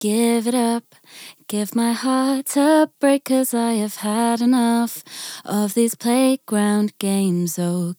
これは中音域から高域にかけて音をクリアにしてくれるエフェクターです。